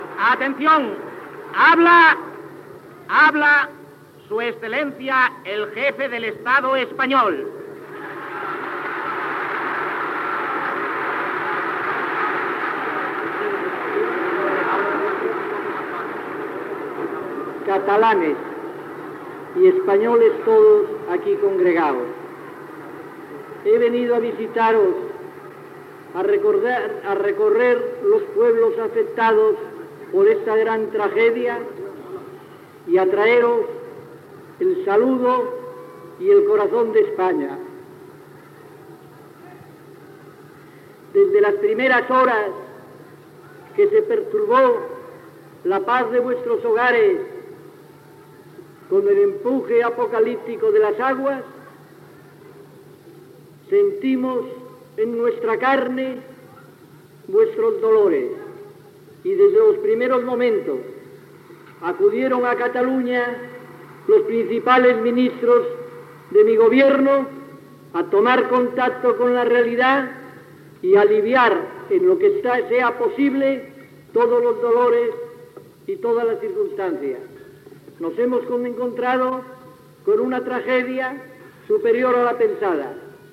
Paraules del cap d'Estat Francisco Franco, des del balcó de l'Ajuntament de Terrassa en la seva visita a la ciutat després de les riuades i inudacions patides dies ençà
Informatiu